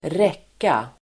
Uttal: [²r'ek:a]